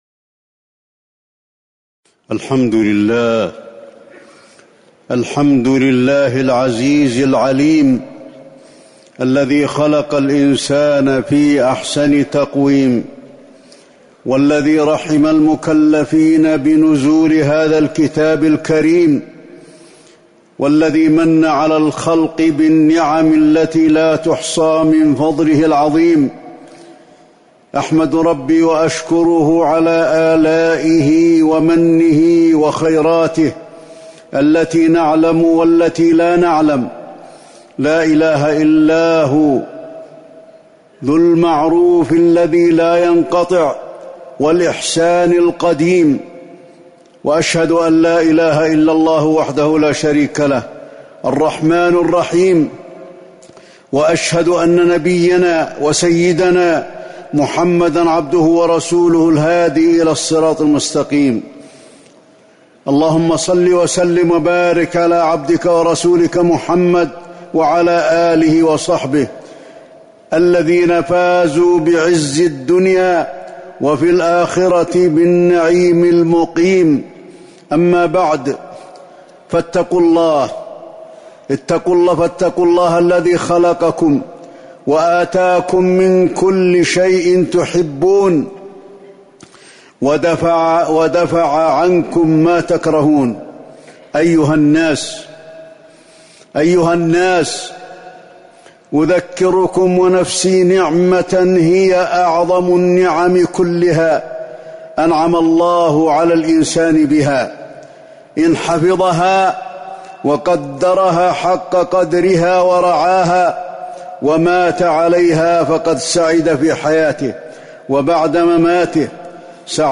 تاريخ النشر ٢٣ ربيع الثاني ١٤٤١ هـ المكان: المسجد النبوي الشيخ: فضيلة الشيخ د. علي بن عبدالرحمن الحذيفي فضيلة الشيخ د. علي بن عبدالرحمن الحذيفي الفطرة